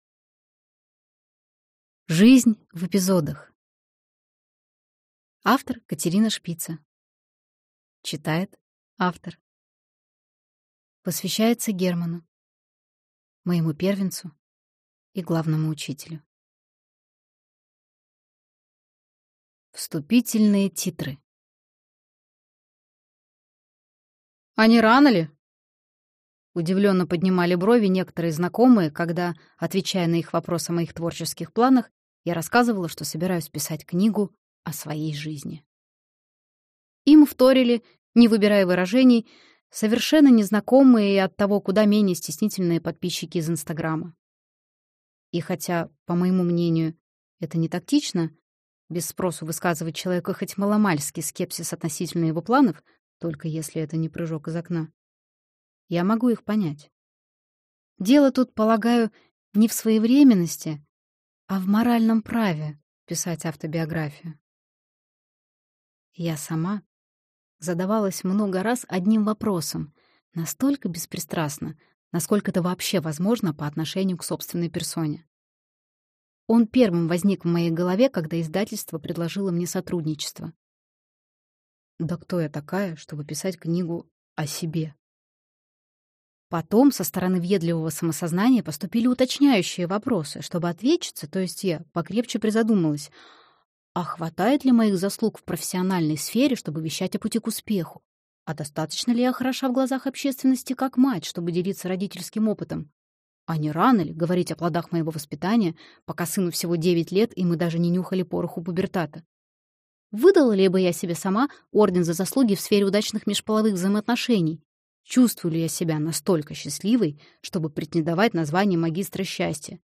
Аудиокнига Жизнь в эпизодах | Библиотека аудиокниг